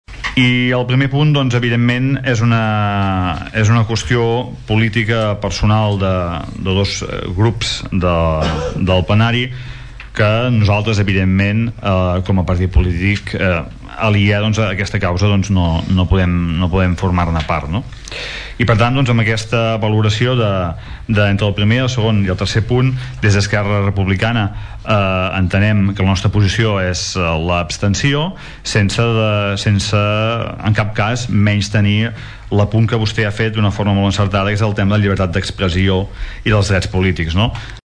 El ple de l’Ajuntament va debatre ahir una moció presentada pel grup municipal de Som Tordera-Entesa en defensa dels drets polítics i civils de les persones, la democràcia i la llibertat d’expressió i en contra de les conductes que vulnerin aquests principis fonamentals.
El regidor d’ERC+Gent de Tordera, Xavier Pla creu que és una qüestió política dels dos grups i que no els pertoca posicionar-se.